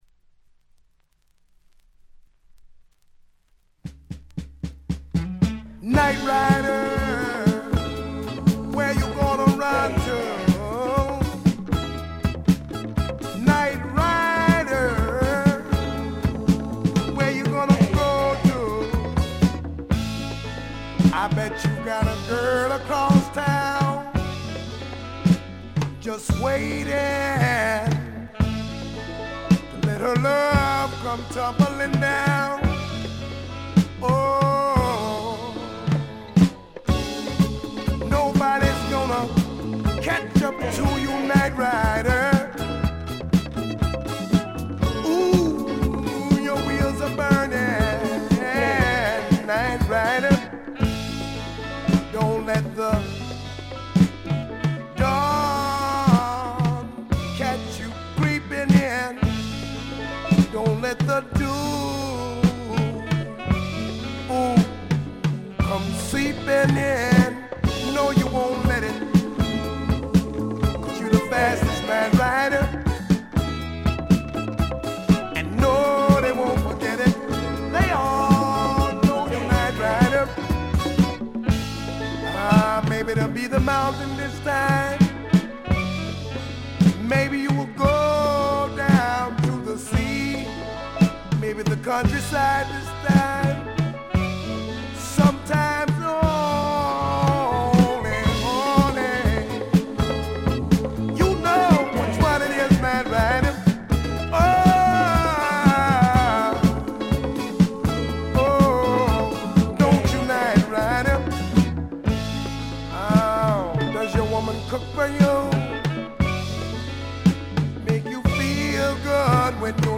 ほとんどノイズ感無し。
試聴曲は現品からの取り込み音源です。
Recorded at Conway Recorders Co., Hollywood, Aug. 1976.